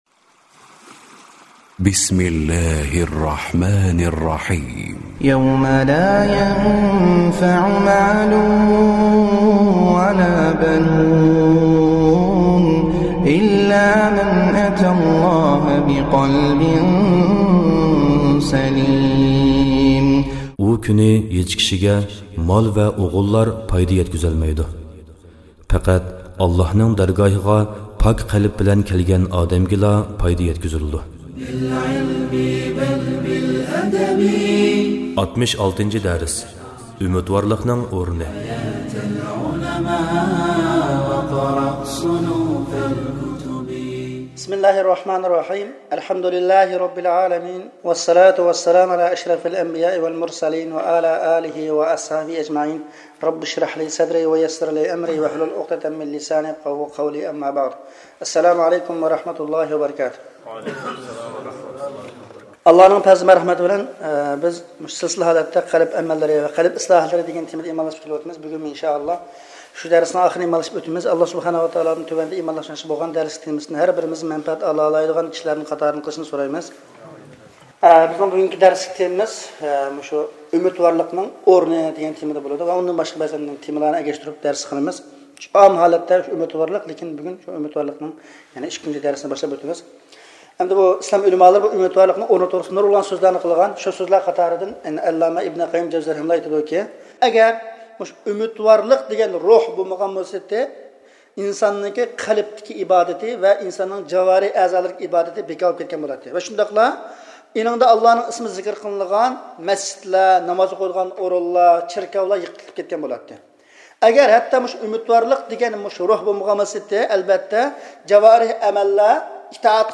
ئاۋازلىق دەۋەت